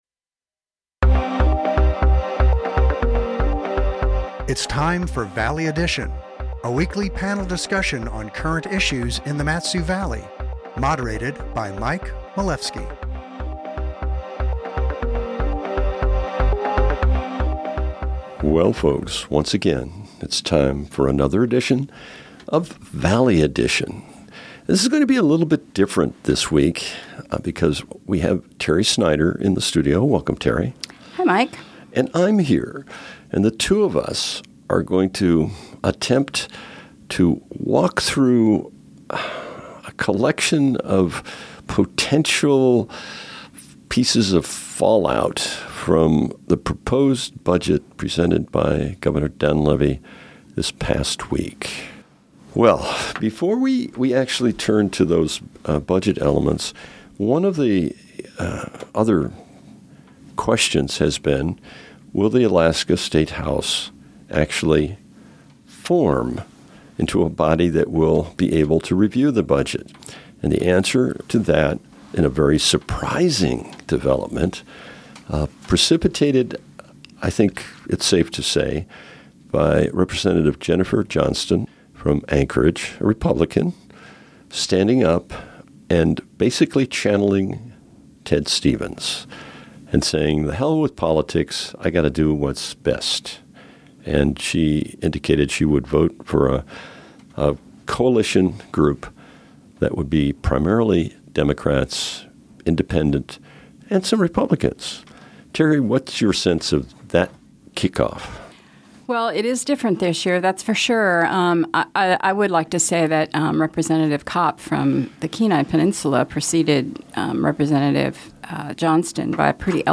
moderates a panel on current issues in the Valley